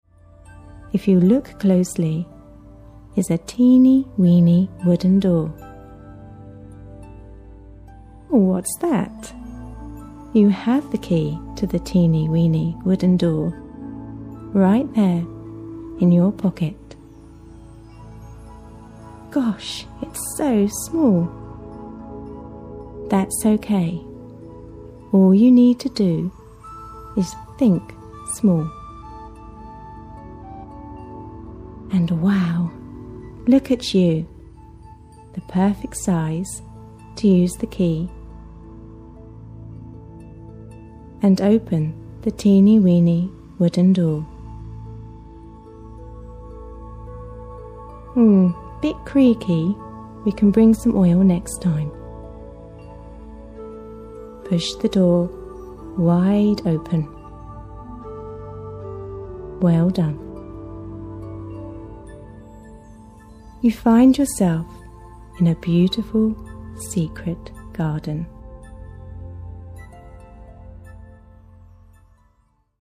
faerie_guided_meditations_for_children.mp3